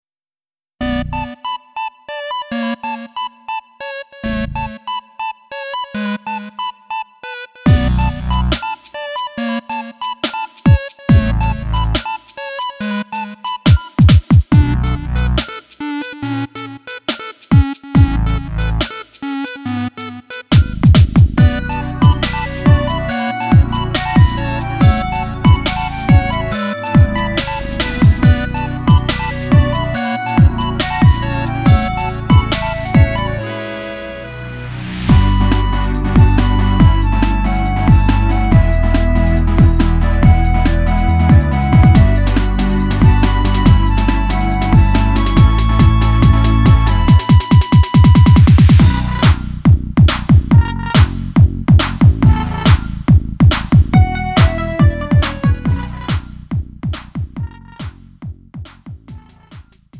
Lite MR